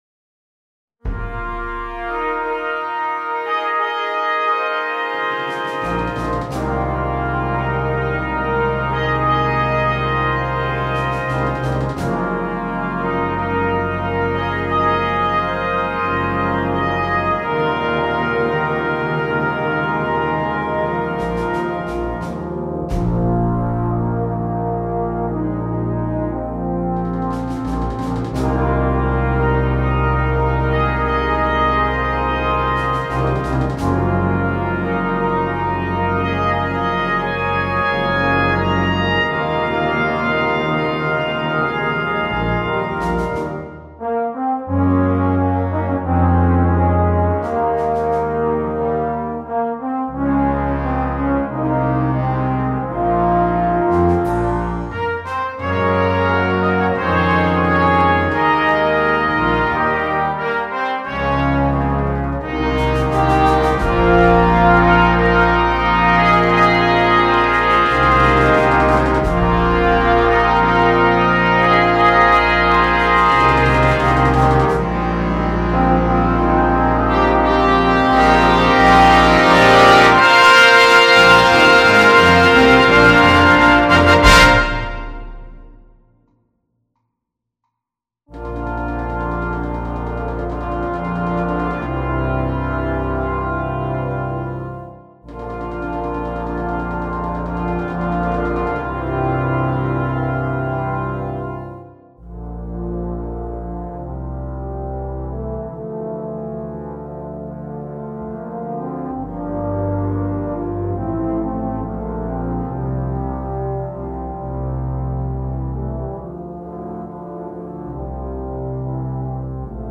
2. Brass Band
Full Band
without solo instrument
Original Composition